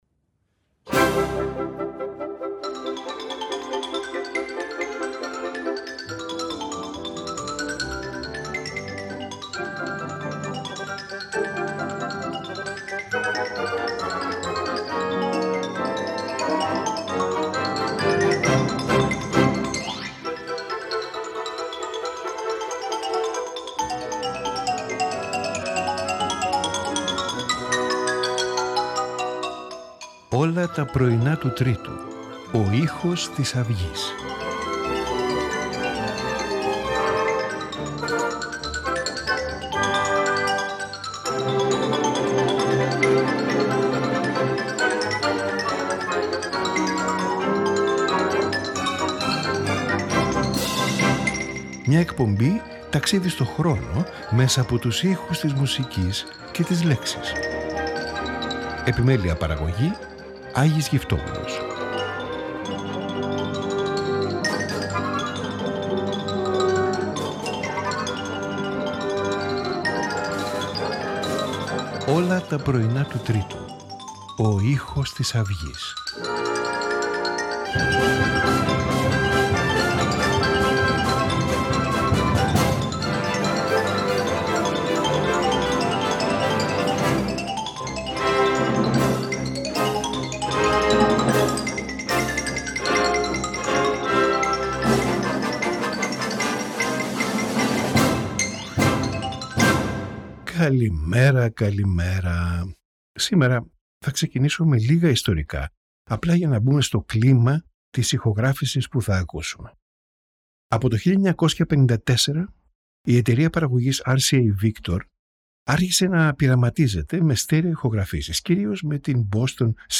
Piano Sonata
Oboe Concerto
Polonaise for Violin and Orchestra